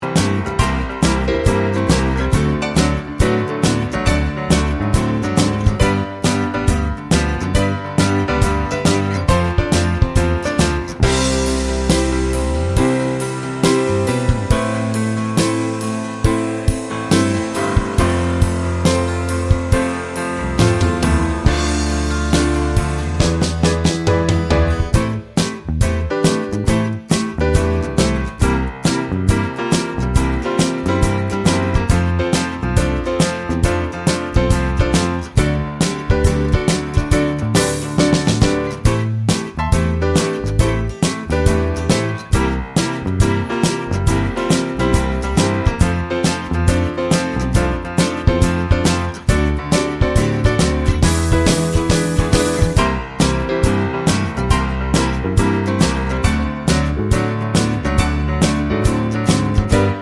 No Piano or Harmonica Pop (1970s) 4:45 Buy £1.50